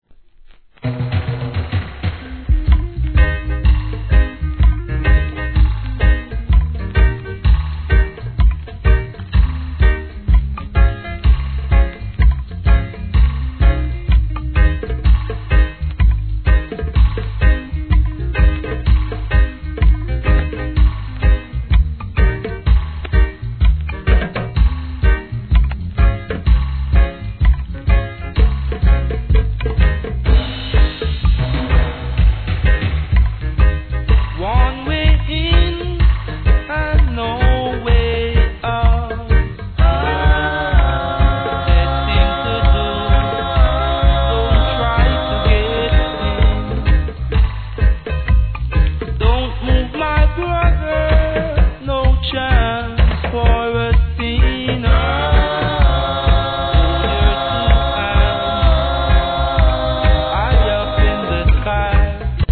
REGGAE
ゆったりとしたRHYTHMに、落ち着いたヴォーカル&バックコーラスが抜群に渋い1978年作品!!